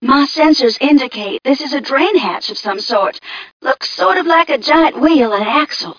1 channel
mission_voice_m3ca029.mp3